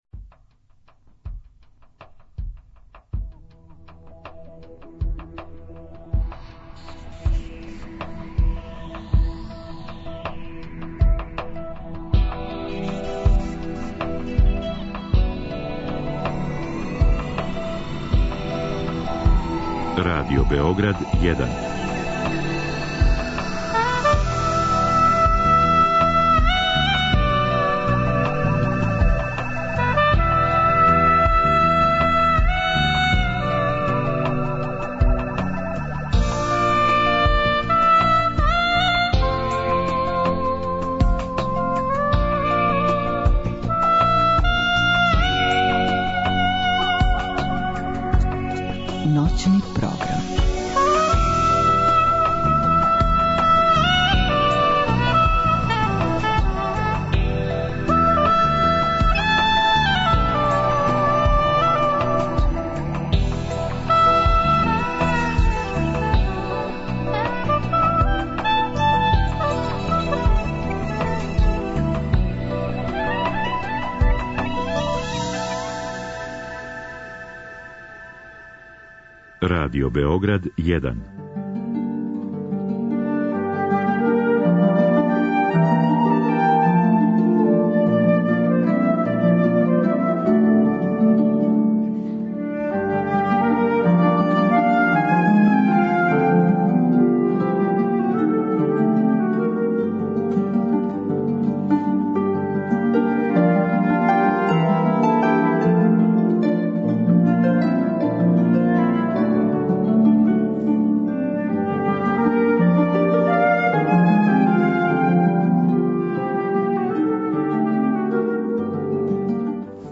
Од 02-04 имаћете прилике да чујете композиције Монтевердија, Бетовена, Брајта Шенга, Малера, Рахмањинова и Холста.